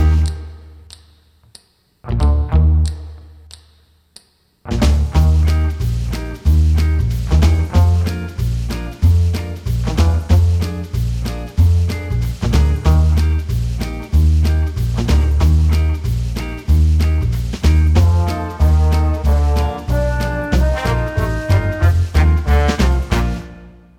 no Backing Vocals Oldies (Female) 2:43 Buy £1.50